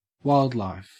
Ääntäminen
IPA : /ˈwaɪldlaɪf/